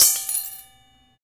Index of /90_sSampleCDs/Roland L-CD701/PRC_Trash+Kitch/PRC_Kitch Tuned
PRC GLASSY05.wav